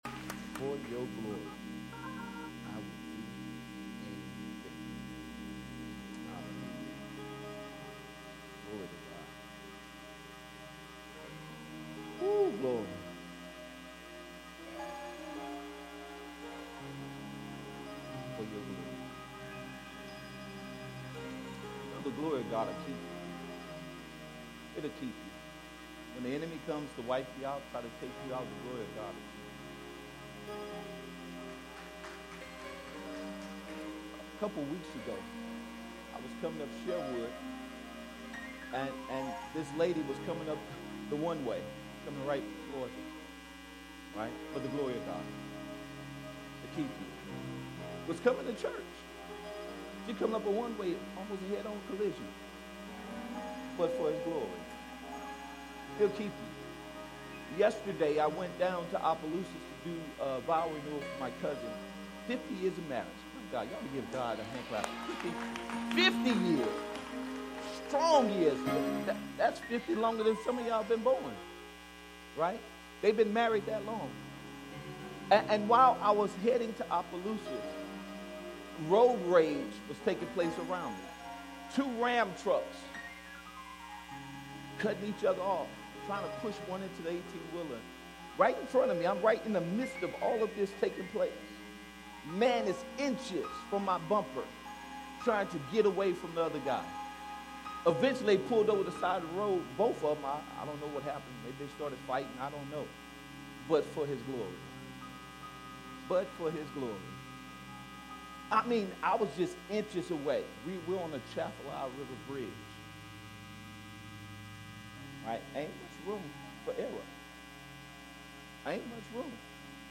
Sermons by Elevate Church of Baton Rouge … continue reading 214 episodes # Religion # Dream Big # Christianity # Elevate Church Of Baton Rouge